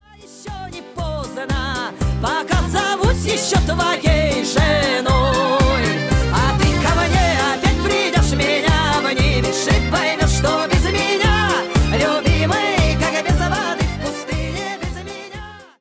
Музыка » Шансон » Викторина
Угадать певицу.